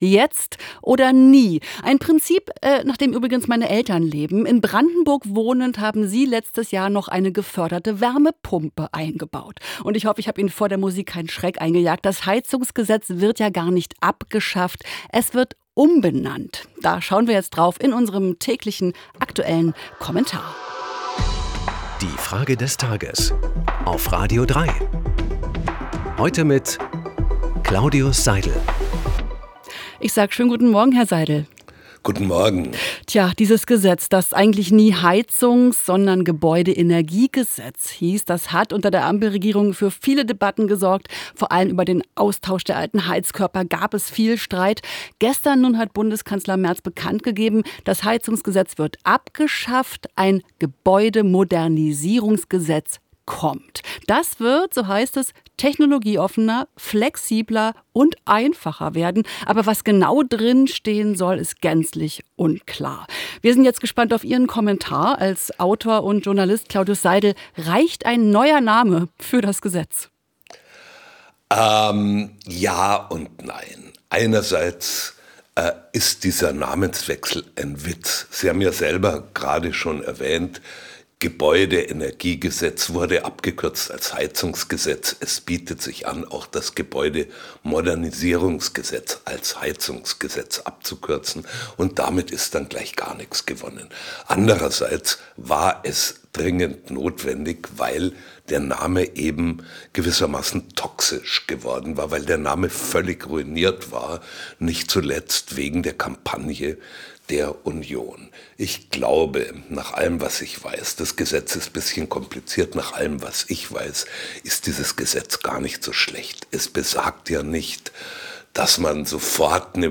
Zehn starke Stimmen im Wechsel "Die Frage des Tages" – montags bis freitags, immer um 8 Uhr 10.